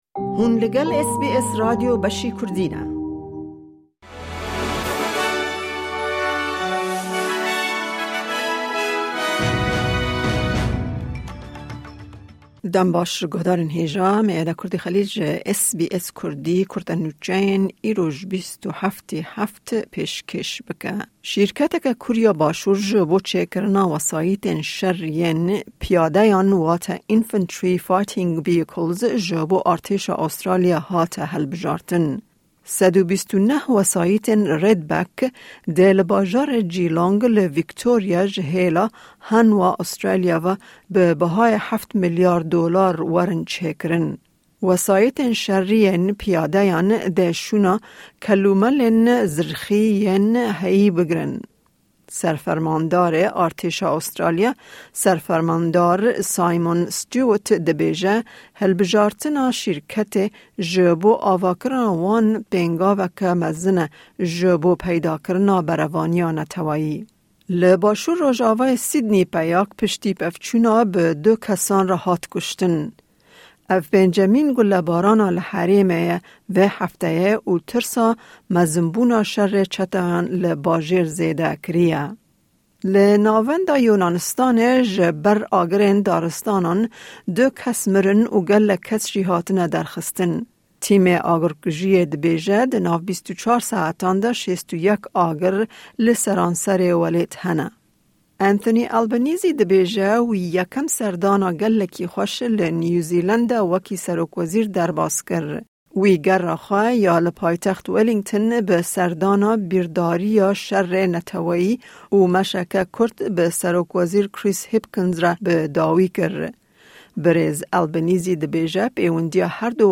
Kurte Nûçeyên roja Pêncşemê 27 Tîrmehê